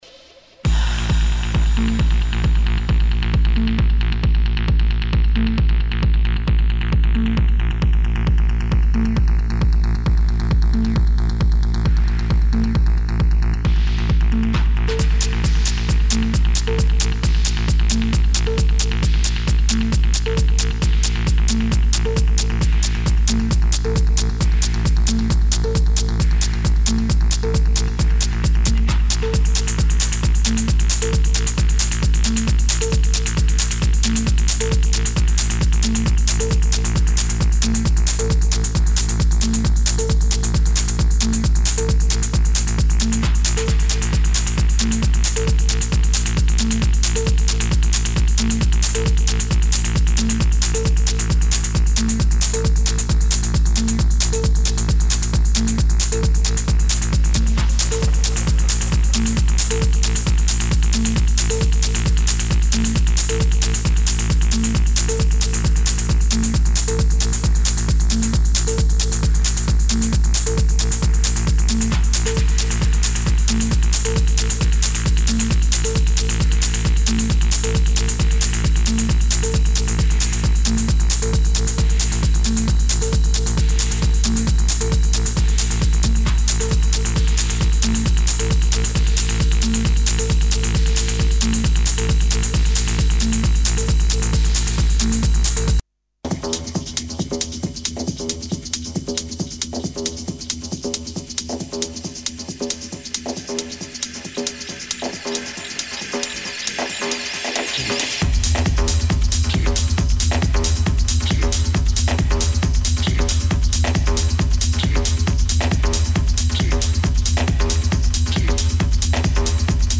Techno-Electro